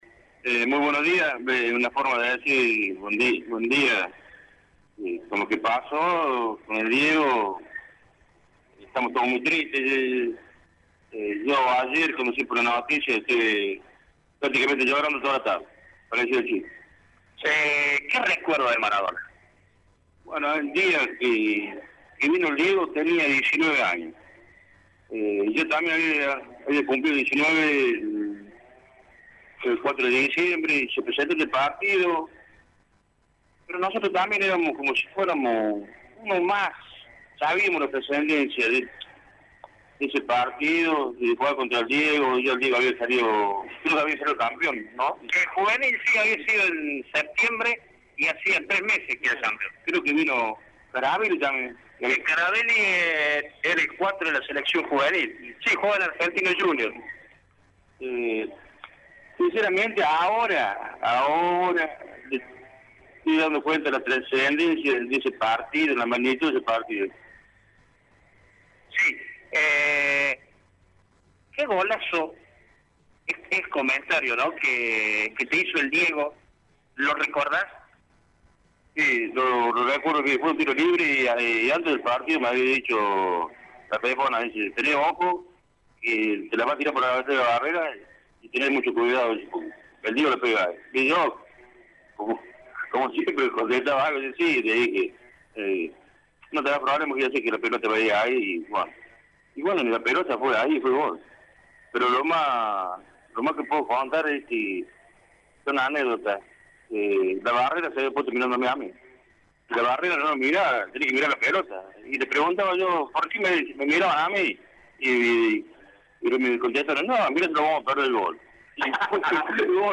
Radio Show dialogó con